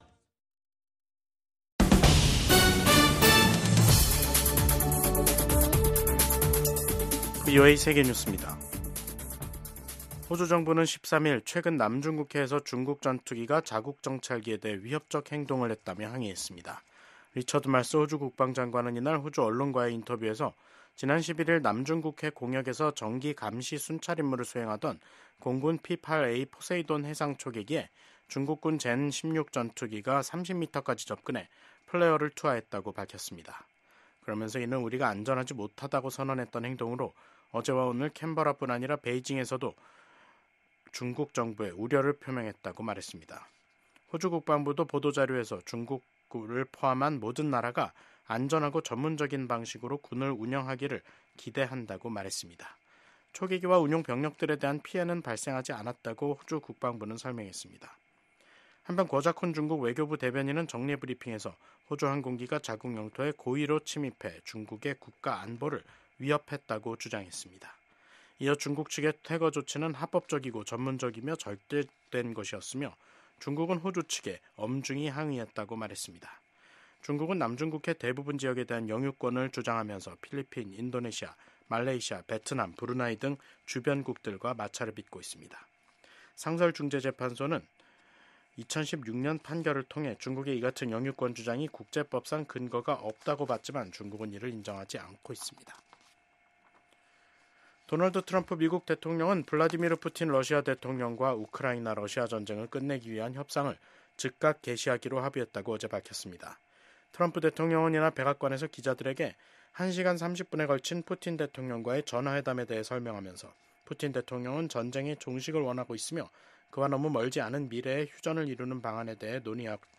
VOA 한국어 간판 뉴스 프로그램 '뉴스 투데이', 2025년 2월 13일 3부 방송입니다. 북한이 남북 화해의 상징인 금강산 관광지구 내 이산가족면회소를 철거 중인 것으로 파악됐습니다. 미국 군함을 동맹국에서 건조하는 것을 허용하는 내용의 법안이 미국 상원에서 발의됐습니다. 미국 경제 전문가들은 트럼프 대통령의 철강∙알루미늄 관세 부과는 시작에 불과하다면서 앞으로 더 많은 관세가 부과돼 미한 경제 관계에 긴장이 흐를 것으로 전망했습니다.